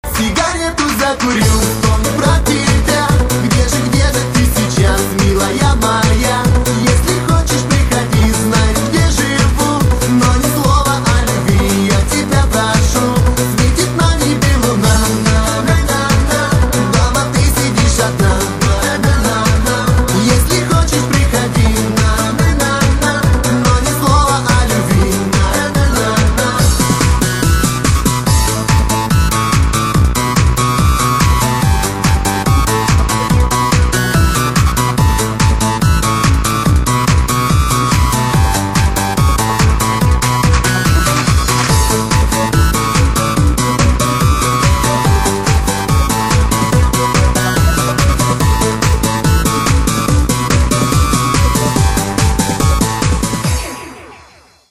• Качество: 192, Stereo
веселые